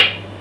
bouncehard1.wav